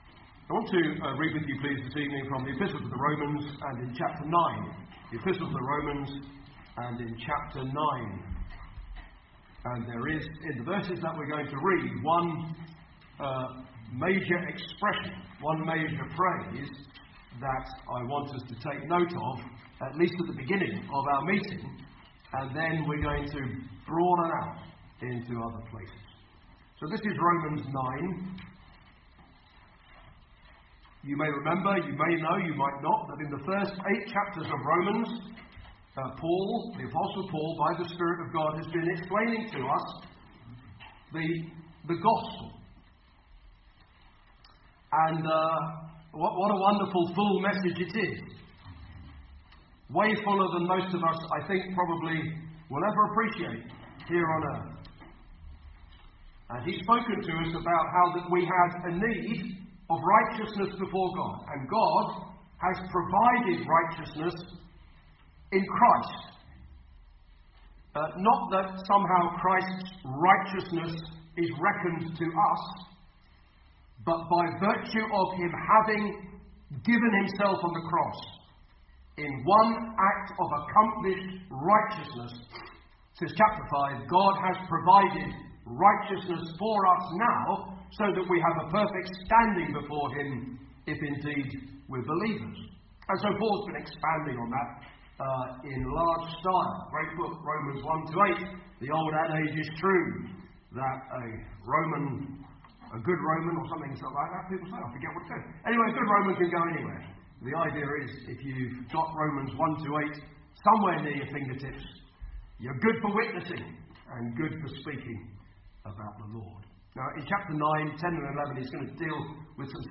(Message preached in Wales, UK).